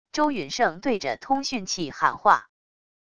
周允晟对着通讯器喊话wav音频